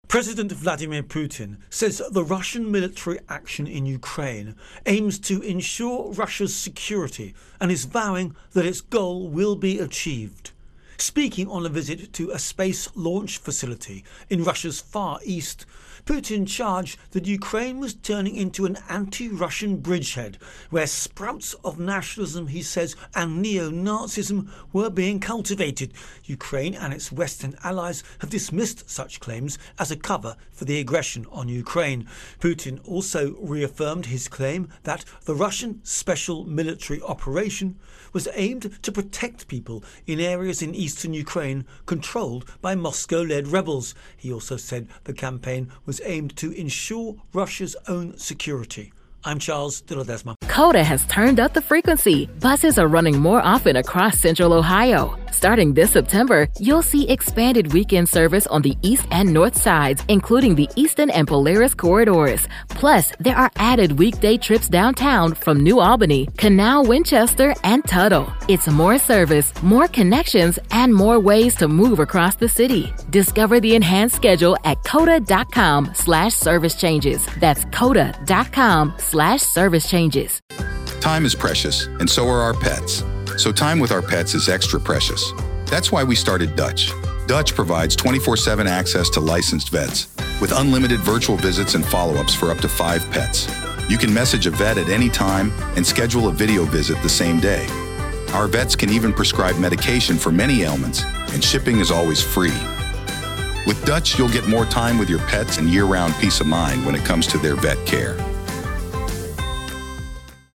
Russia Ukraine War Putin Intro and Voicer